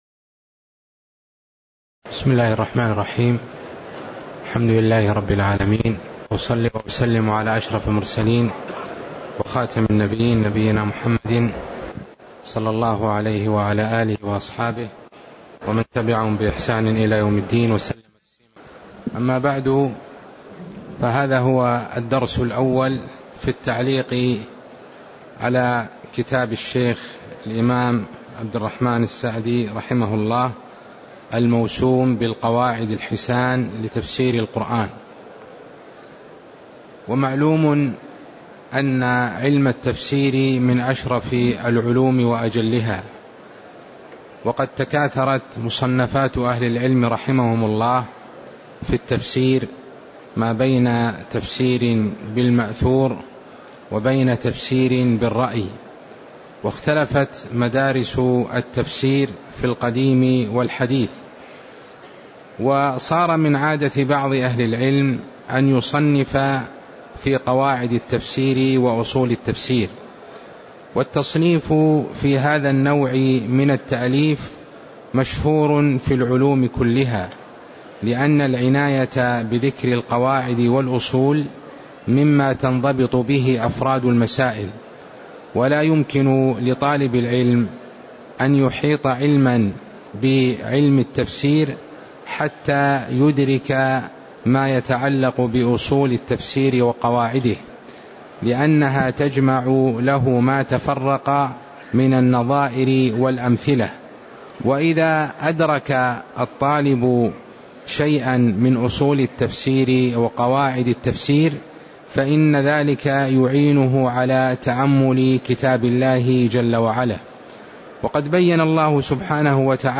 تاريخ النشر ٢٥ ذو الحجة ١٤٣٧ هـ المكان: المسجد النبوي الشيخ